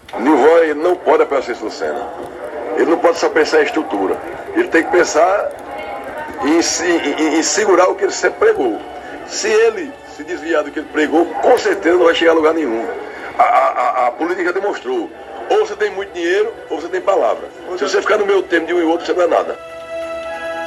Os comentários do deputado foram registrados durante o programa Arapuan Verdade, da Rádio Arapuan FM desta terça-feira (27/02).